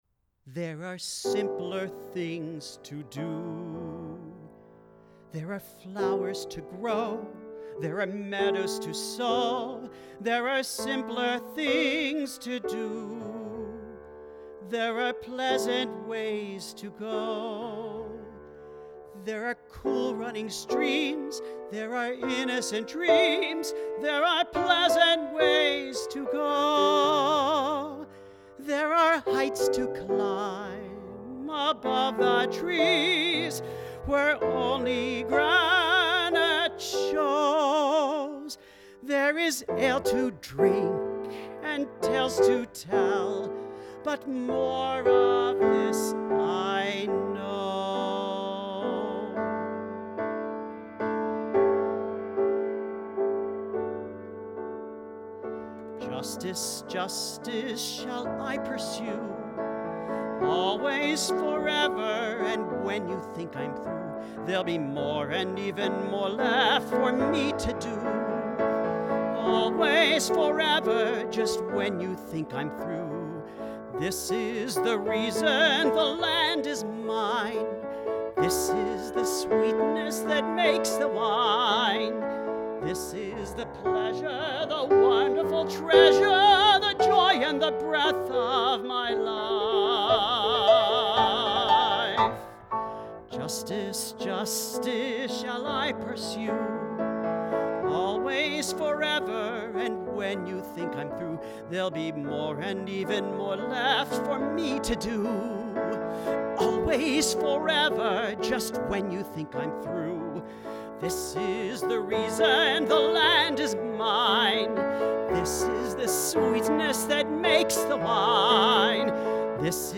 Rehearsal recording
Piano